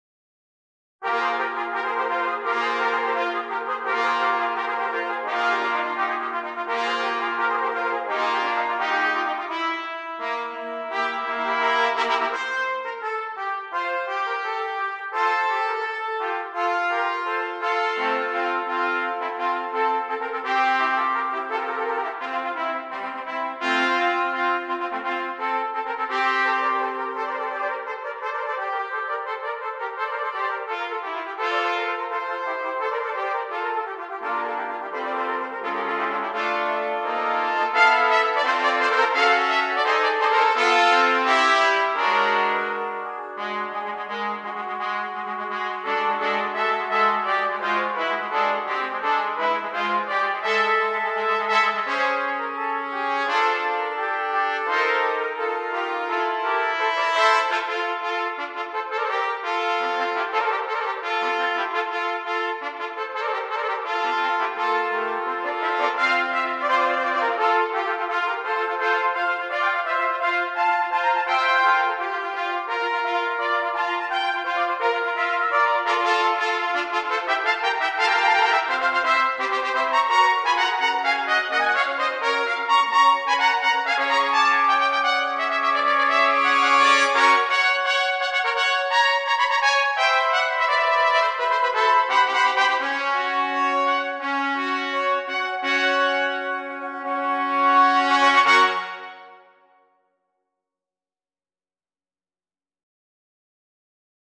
6 Trumpets
with a mariachi feel!